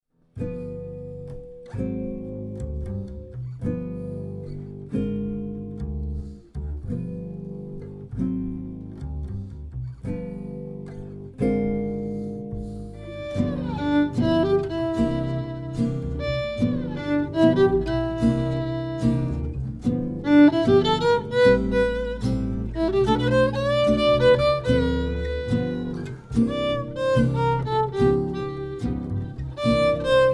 violino
chitarra semiacustica
chitarra acustica
contrabbasso